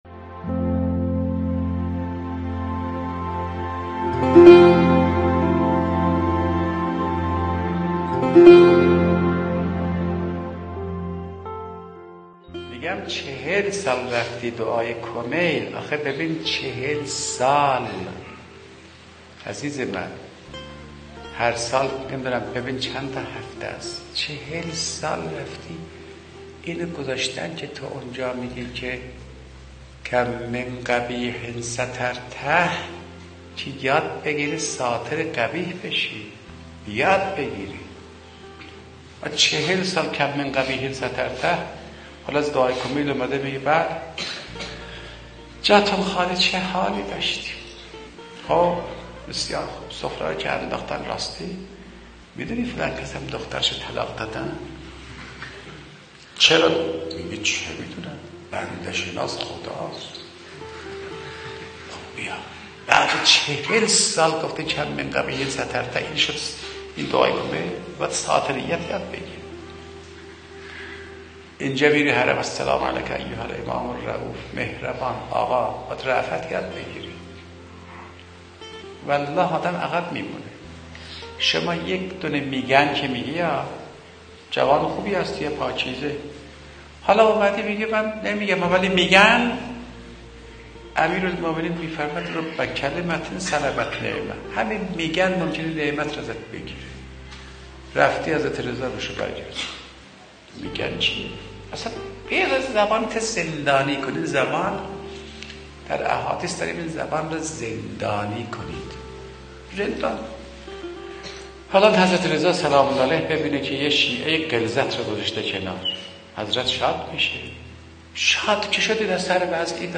سخنرانی | زندانی کردن زبان
سخنرانی حجت الاسلام سیدعبدالله فاطمی نیا | گروه فرهنگی مسجد و حسینیه الزهرا(س)